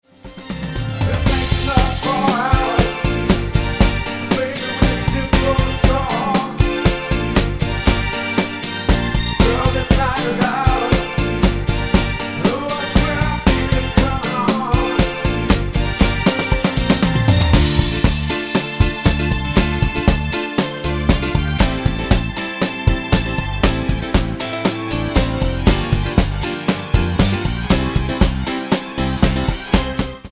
Tonart:F mit Chor